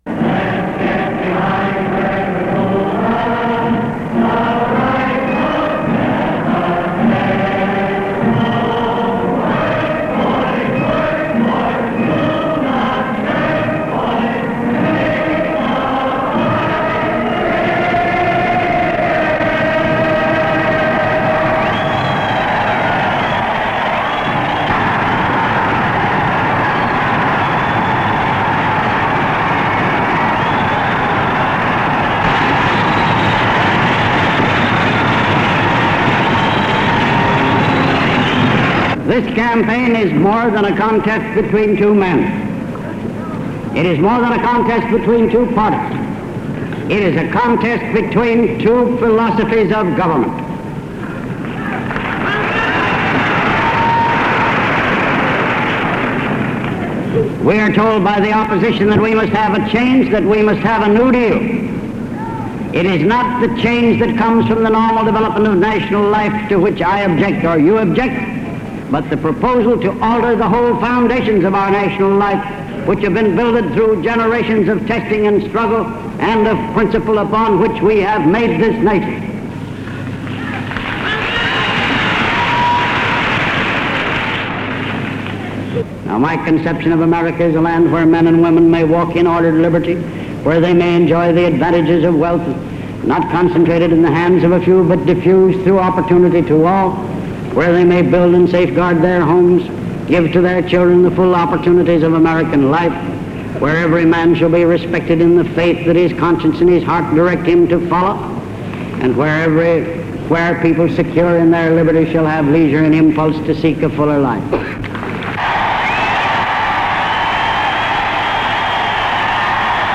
1932 campaign speech
Speakers Hoover, Herbert, 1874-1964
Broadcast on Fox Movietone, 1932.